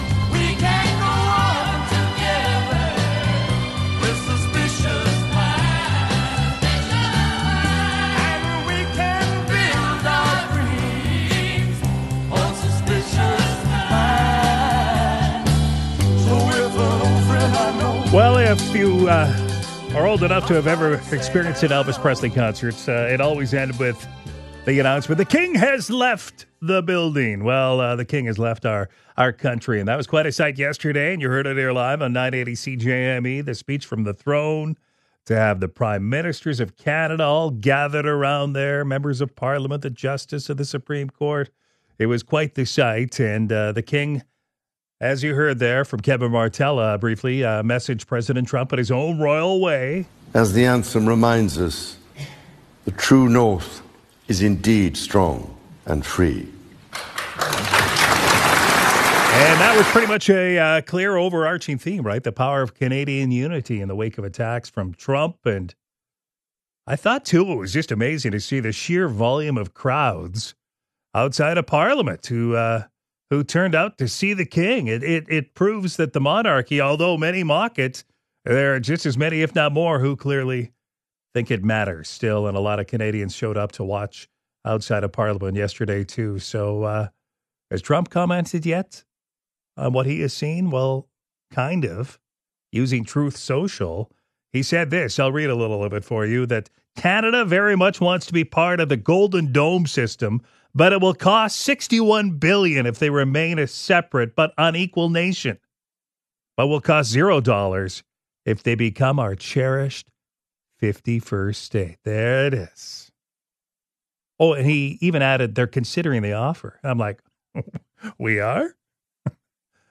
The King has left the building.....errr country! We have highlights of King Charles’ speech from the throne.